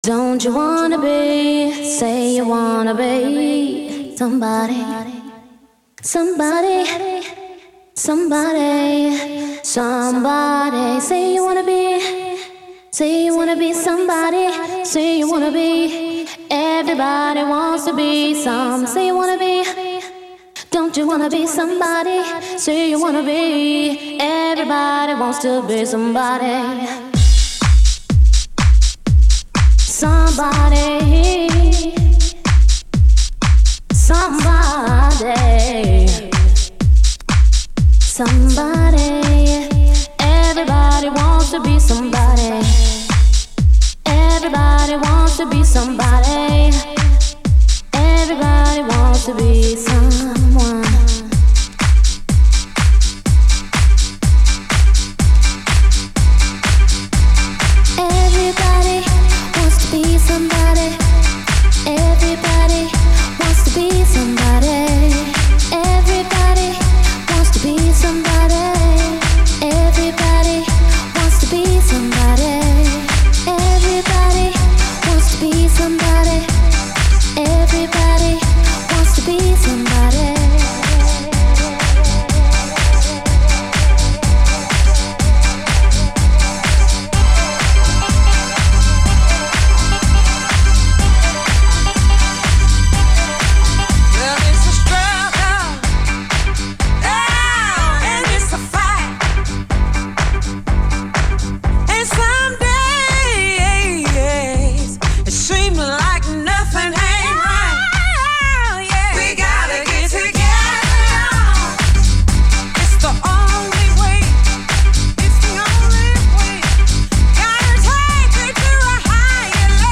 bringing both classic & contemporary dancefloor bangers